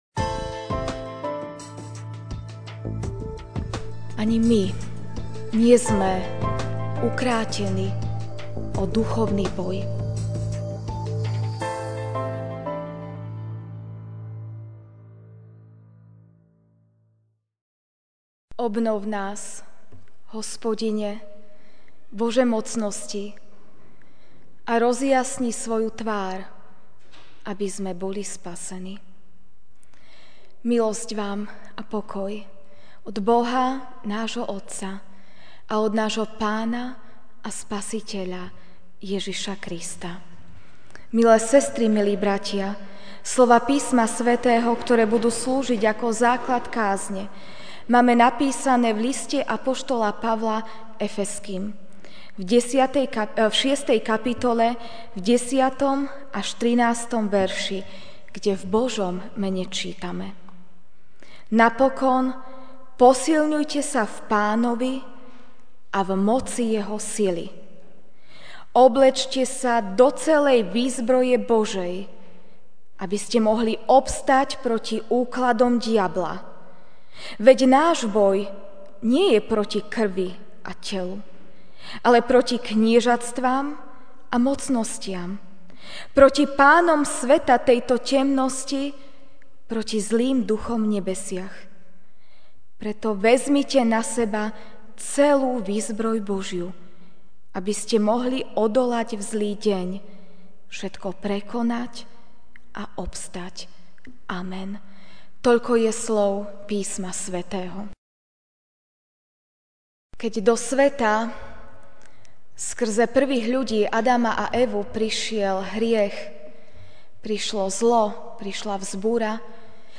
Večerná kázeň: V boji o dokonalosť (Ef. 6, 10-13) Napokon posilňujte sa v Pánovi a v moci Jeho sily.